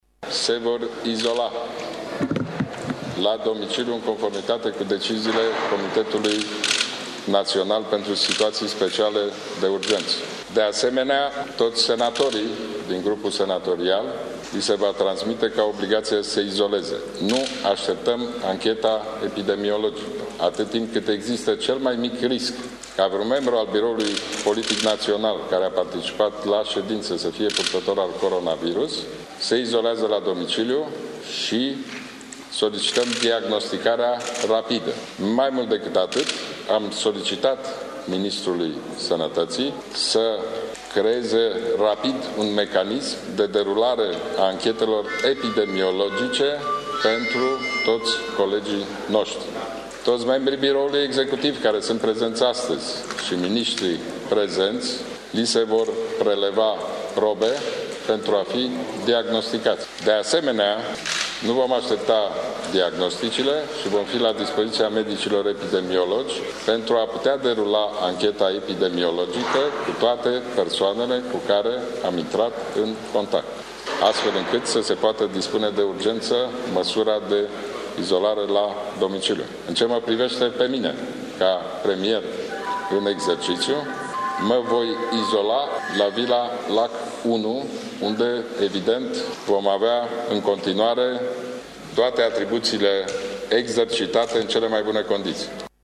De asemenea, membrii Guvernului intră la izolare, a precizat premierul în exercițiu: